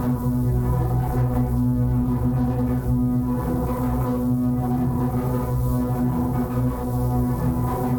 Index of /musicradar/dystopian-drone-samples/Tempo Loops/90bpm
DD_TempoDroneC_90-B.wav